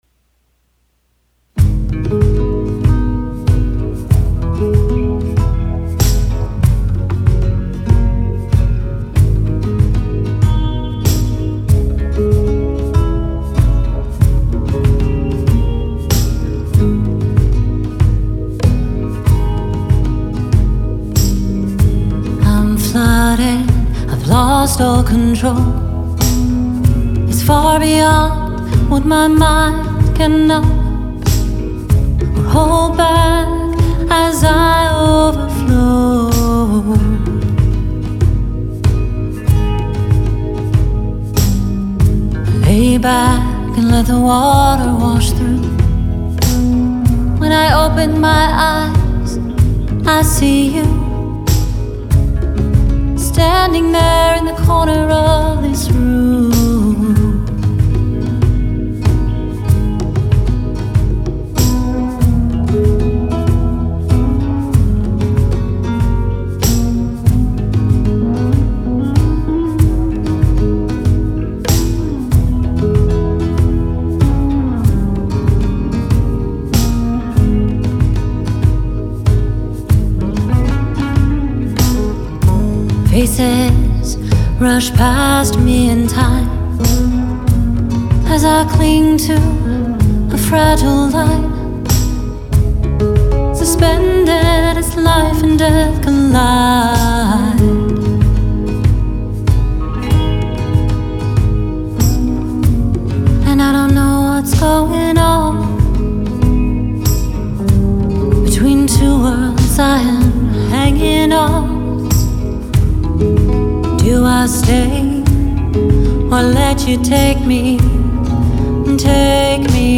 Singer-songwriter